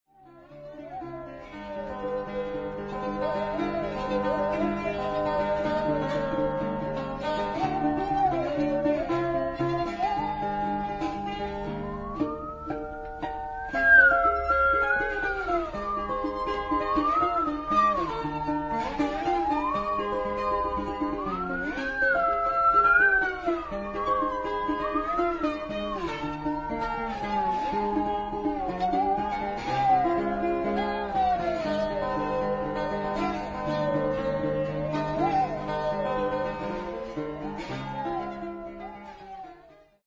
Singende Säge und Gitarre - Berlin
Titel 2 - 5: live at Abitz Courtyard, 23.8.2003